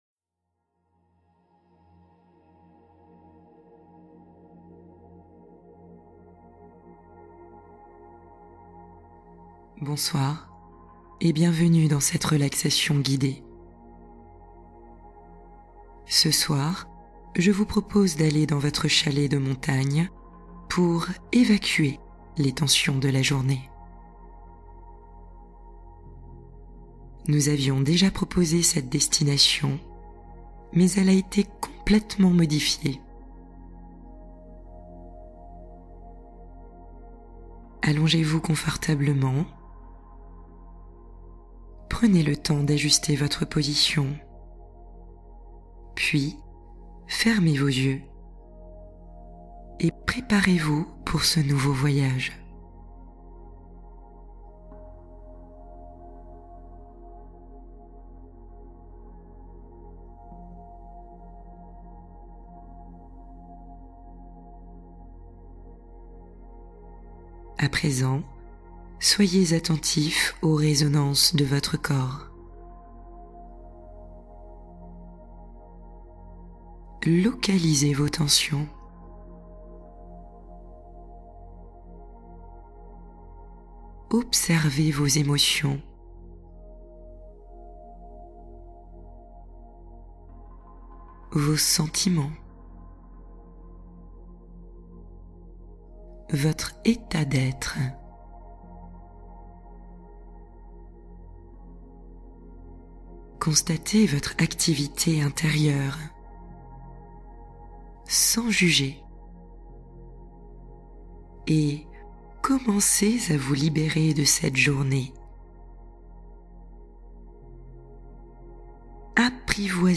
Rencontre intérieure profonde : relaxation guidée pour un apaisement durable